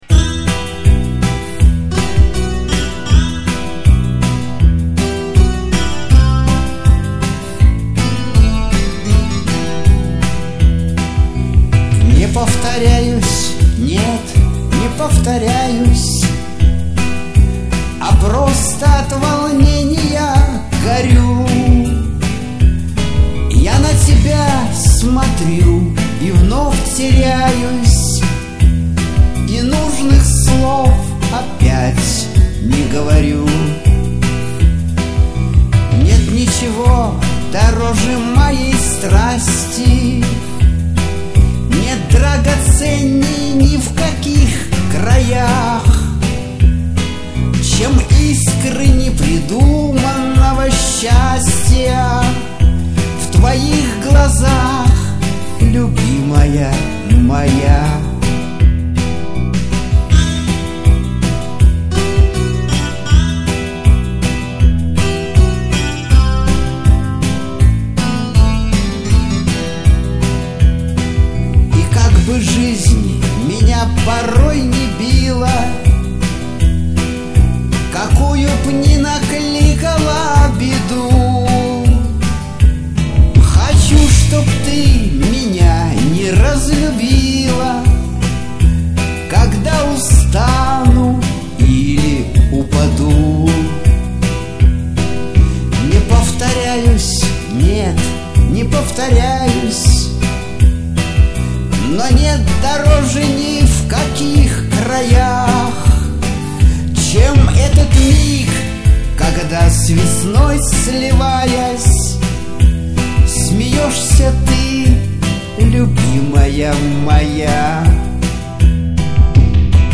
Лирическая музыка